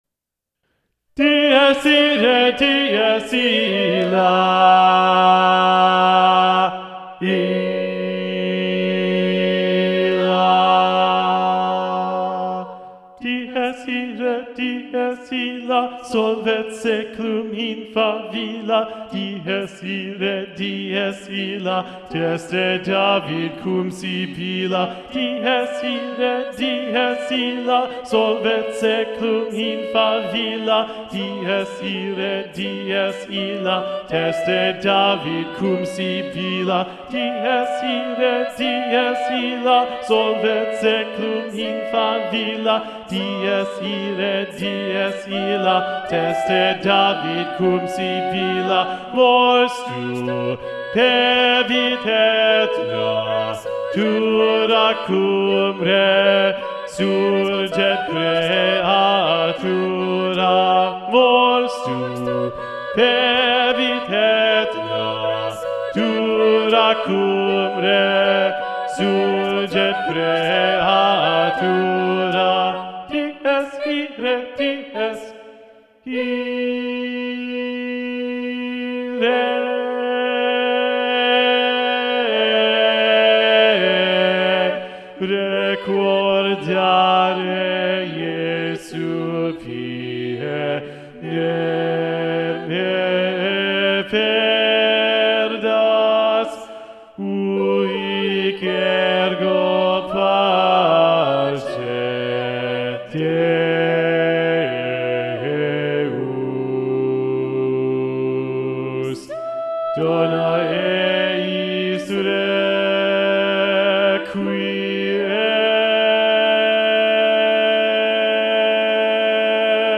- Œuvre pour chœur à 8 voix mixtes (SSAATTBB)
SATB Bass 2 Predominant